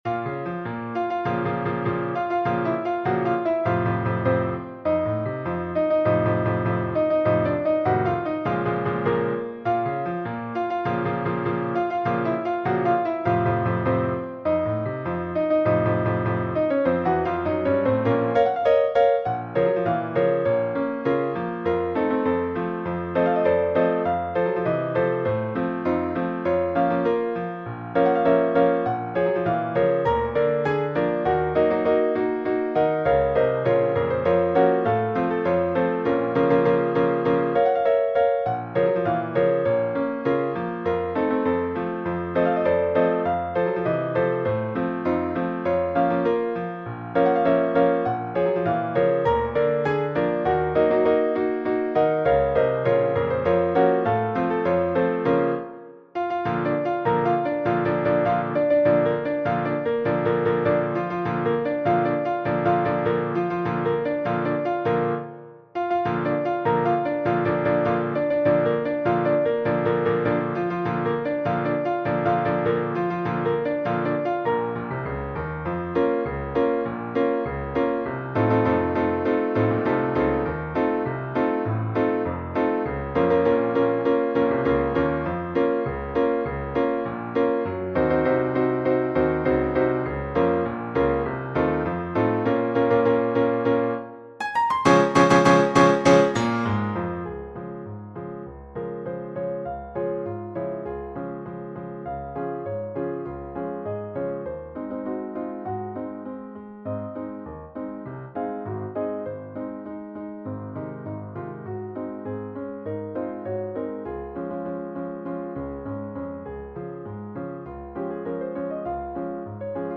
Versión: Arreglo para Piano Solo